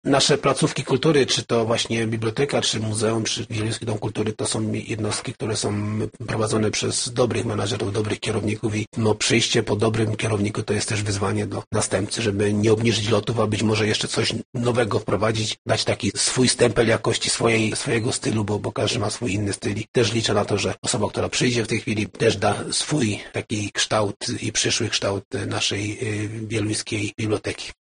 – mówił nam już wcześniej burmistrz Paweł Okrasa .